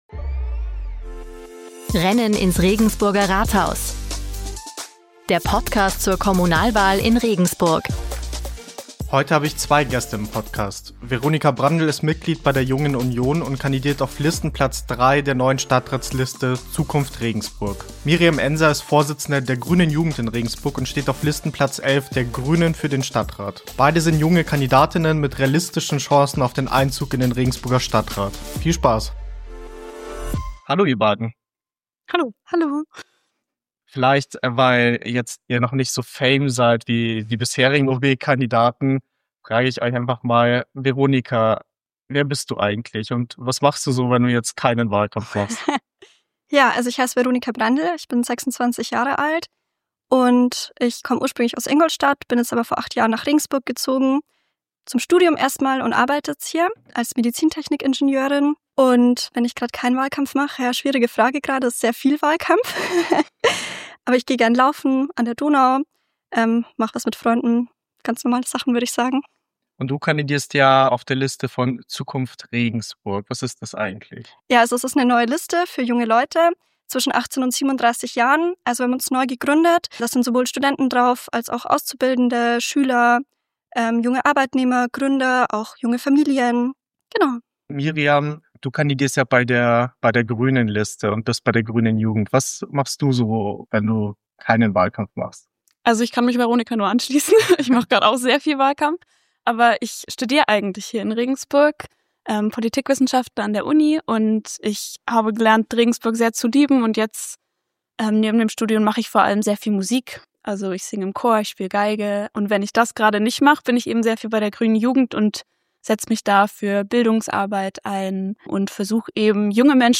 In dieser besonderen Doppel-Episode diskutieren zwei junge Kandidatinnen mit realistischen Chancen auf den Einzug in den Stadtrat über ihre unterschiedlichen Wege in die Kommunalpolitik.